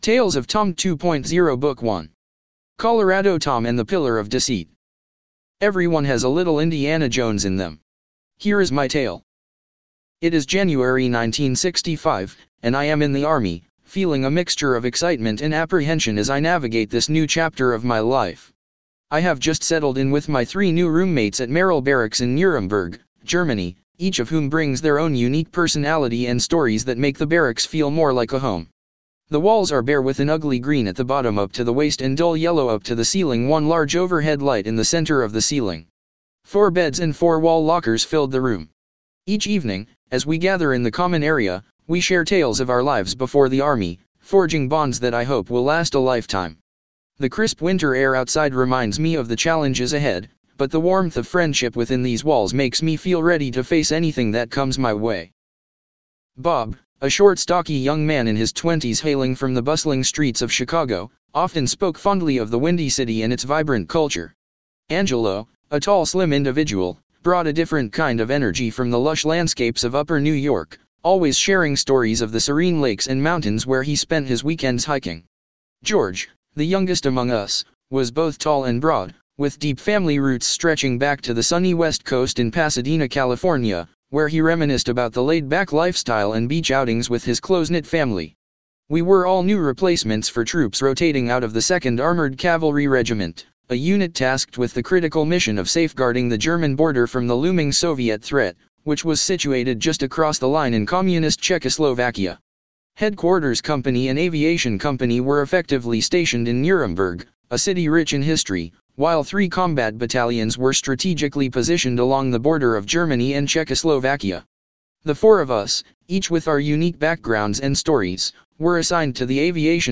Audio Podcast of the complete book 2 hr 18Min